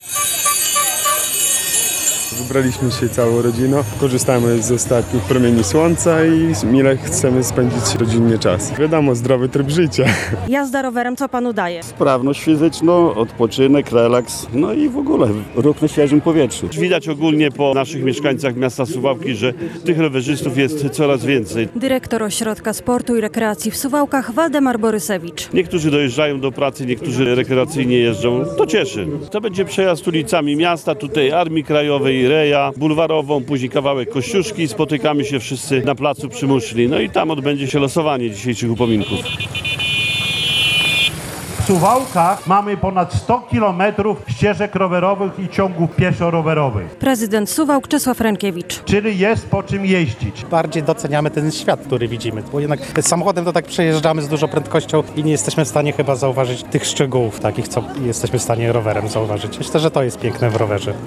Europejski Dzień Bez Samochodu w Suwałkach - relacja